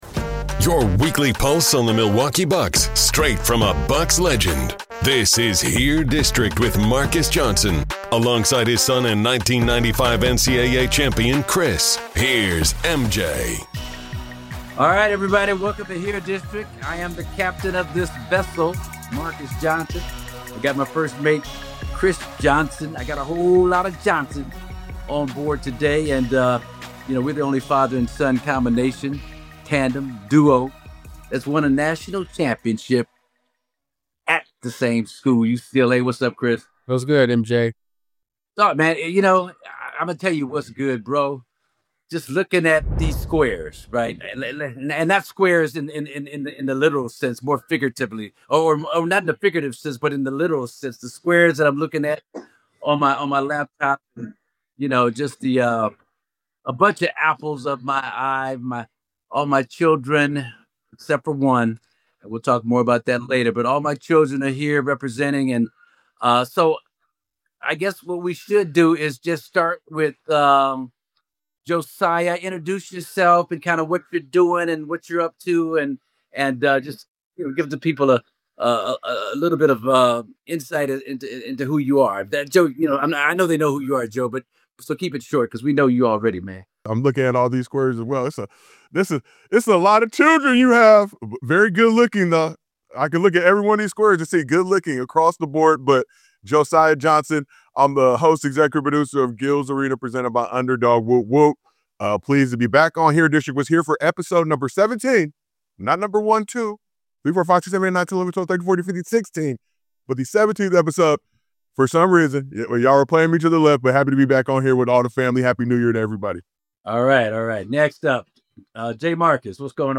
It’s a rare, candid, and heartfelt conversation about family, competition, culture, and the bonds that shaped them.